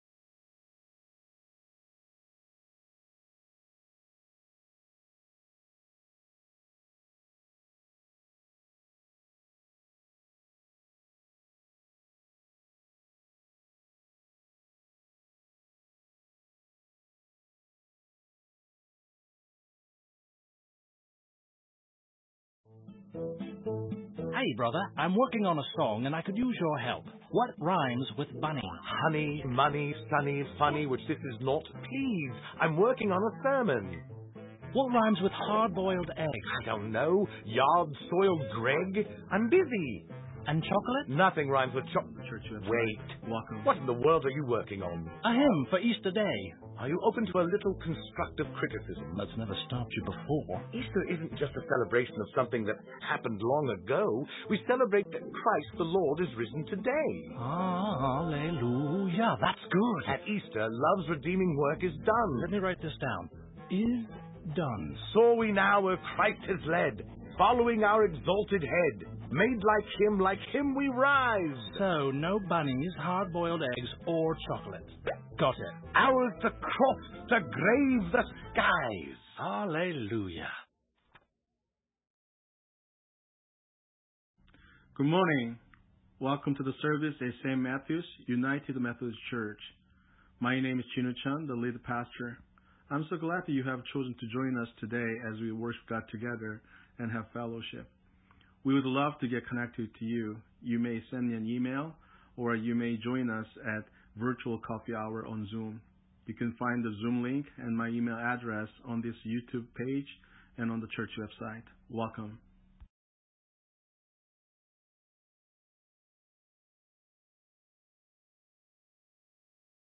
Sermon:God Is Not Angry at You!
2021 We are RECEIVED as the Beloved of God The actual worship service begins 15 minutes into the recordings.